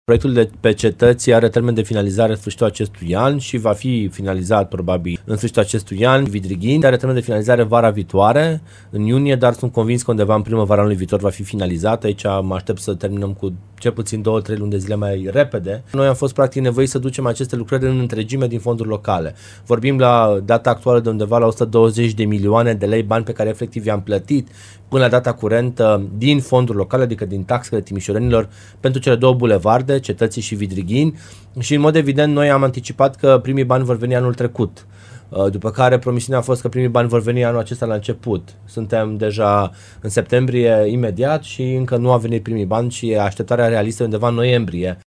Viceprimarul Ruben Lațcău a explicat, la Radio Timișora, că problema la cele două șantiere este cauzată de întârzierea Ministerului Fondurilor Europene în a face plățile din bani europeni.